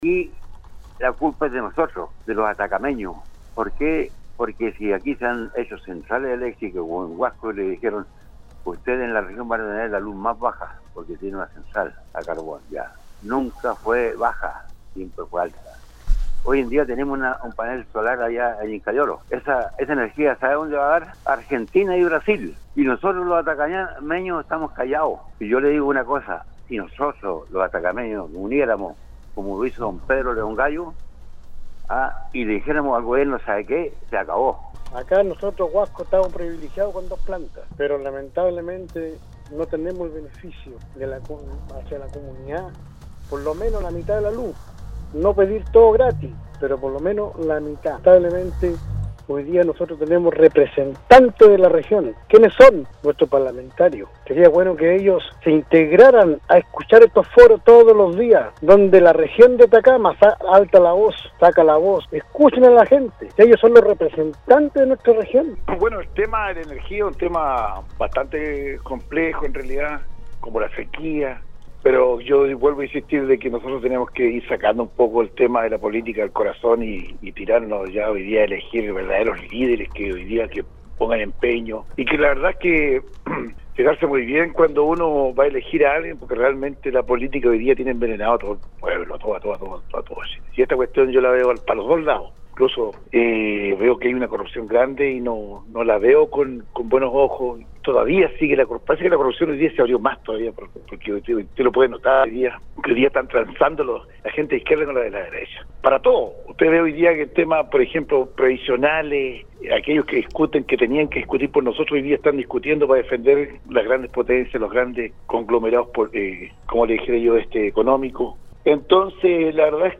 Por otro lado, muchas fueron los llamados y mensajes en relación a las inquietudes sobre los trabajos que se están haciendo en la región de Atacama con las plantas de energía solar, las cuales critican porque los costos de la luz siguen aumentando, sin traer ningún beneficio para los atacameños, incluso recalcaron que se desvían esos recursos a otras poblaciones.